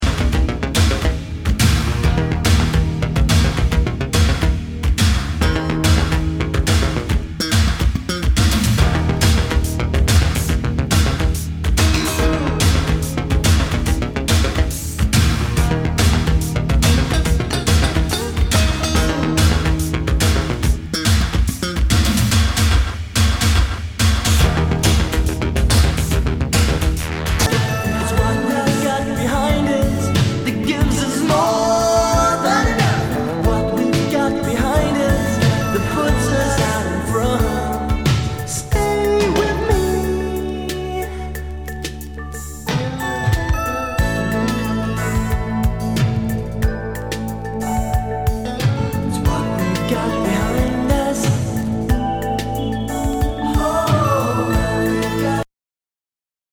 ROCK/POPS/INDIE